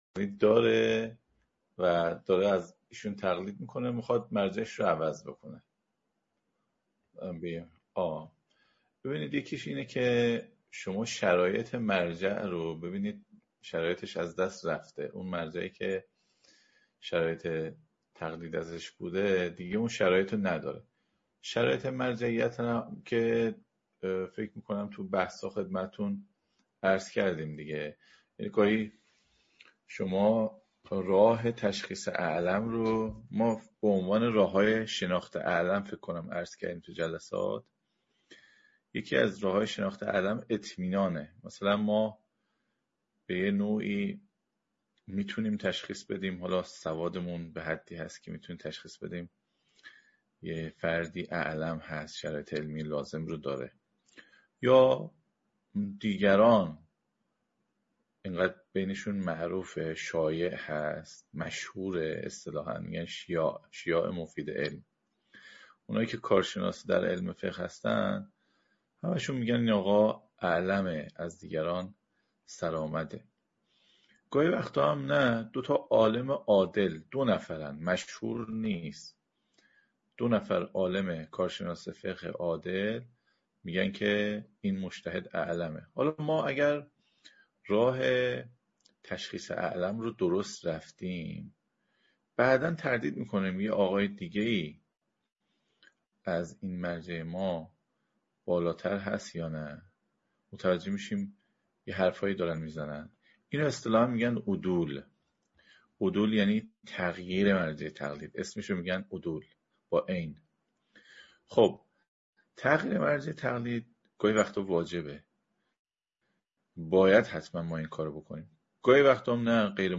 پایه‌ نیایش (احکام عبادات) - جلسه-پرسش-و-پاسخ